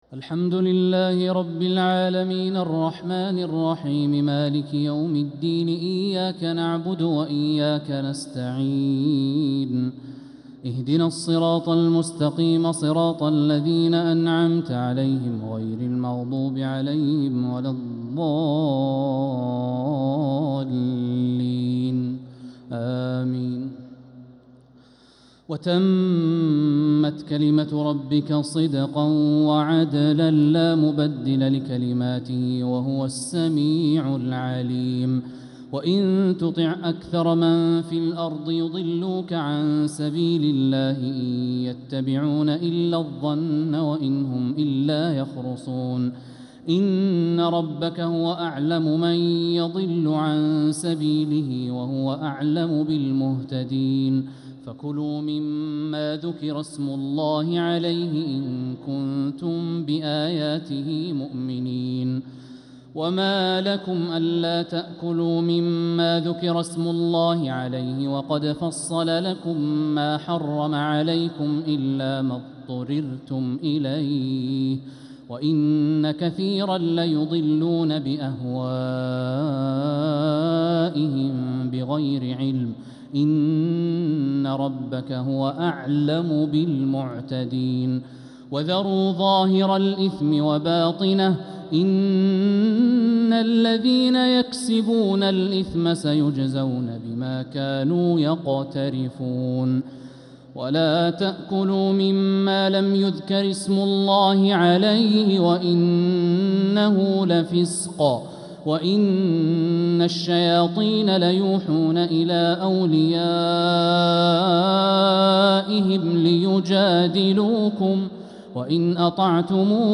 تراويح ليلة 10 رمضان 1446هـ من سورة الأنعام (115-150) | Taraweeh 10th niqht Ramadan Surat Al-Anaam 1446H > تراويح الحرم المكي عام 1446 🕋 > التراويح - تلاوات الحرمين